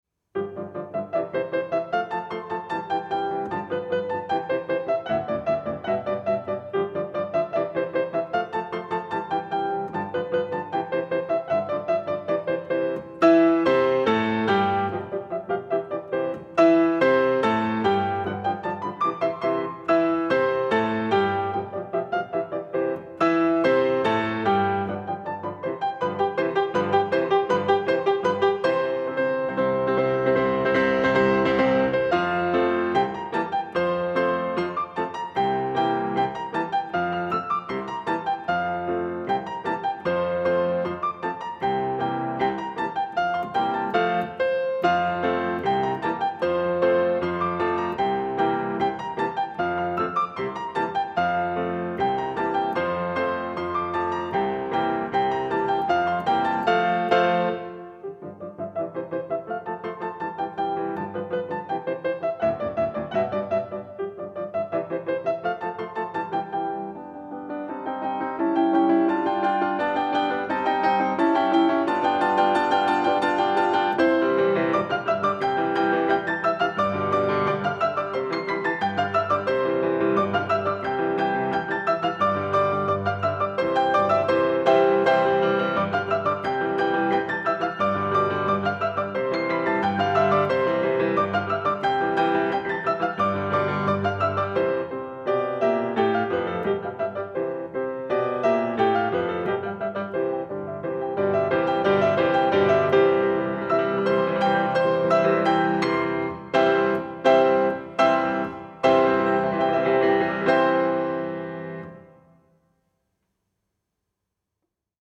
Epoque :  Romantique
Enregistrement audio Piano seul
galop-infernalpiano-seul-1.mp3